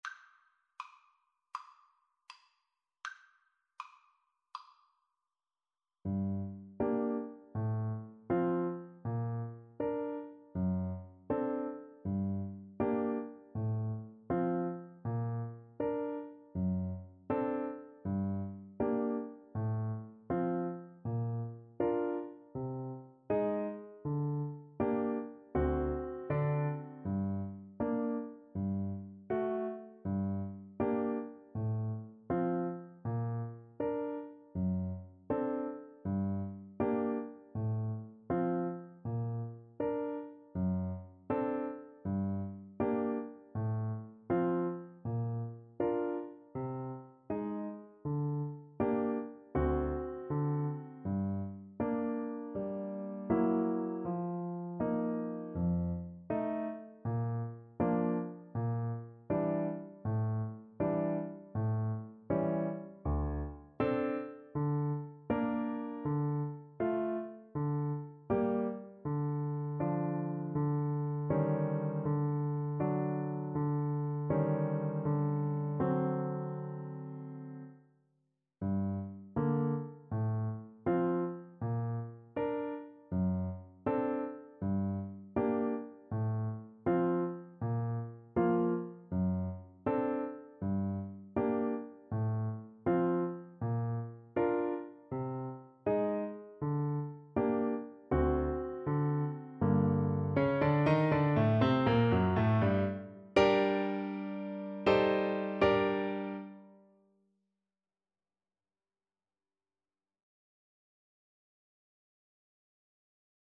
Play (or use space bar on your keyboard) Pause Music Playalong - Piano Accompaniment Playalong Band Accompaniment not yet available reset tempo print settings full screen
Andante = c.80
G minor (Sounding Pitch) D minor (French Horn in F) (View more G minor Music for French Horn )
Classical (View more Classical French Horn Music)